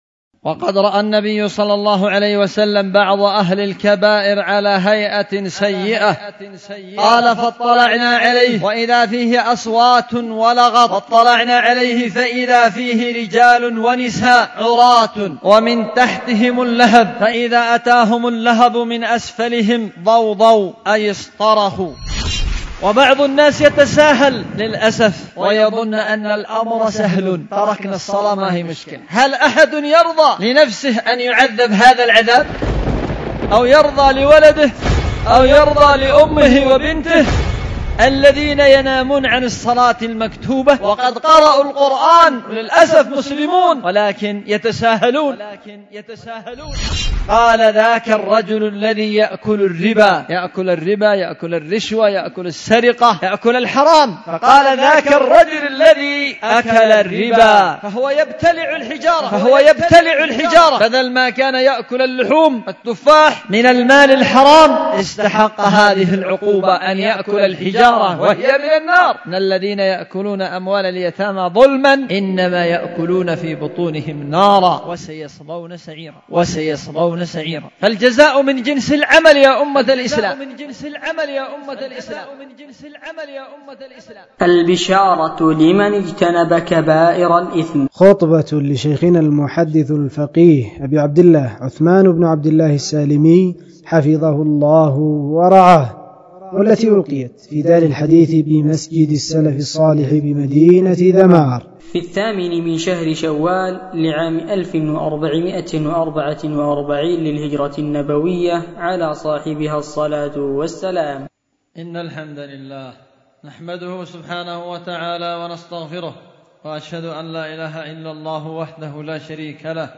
خطبة
ألقيت في دار الحديث بمسجد السلف الصالح بذمار